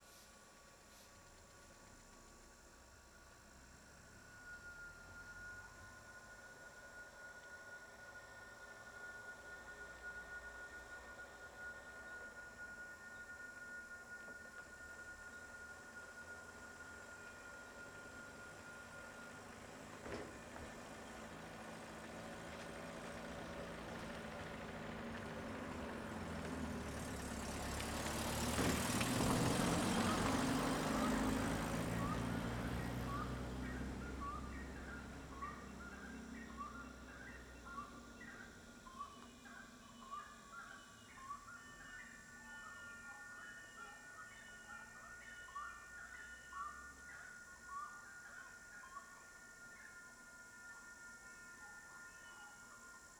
Caminhonete passando final da rua com coro de cigarras e passaro Caminhonete , Cigarra , Dia , Pássaros , Rua , Tarde Alto Paraíso de Goiás Surround 5.1
CSC-14-067-LE - Caminhonete passando final da rua com coro de cigarras e passaro.wav